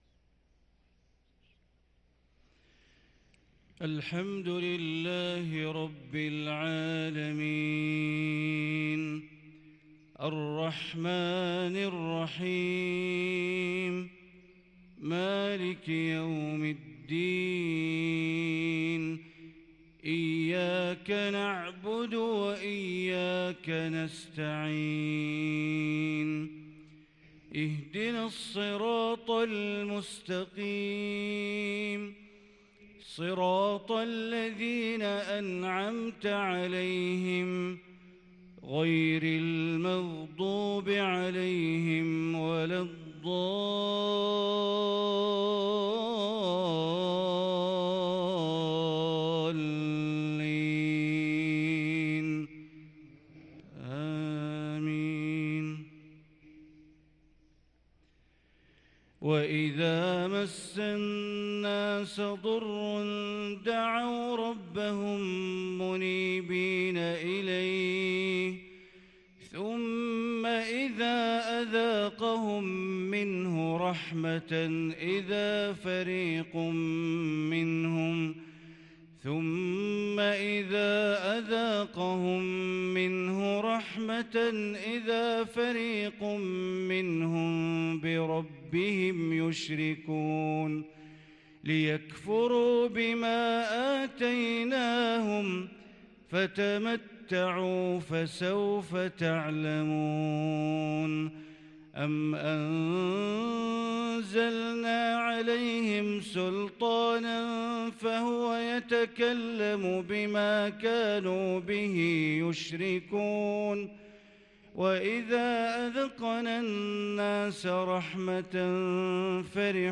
صلاة الفجر للقارئ بندر بليلة 10 ربيع الآخر 1444 هـ
تِلَاوَات الْحَرَمَيْن .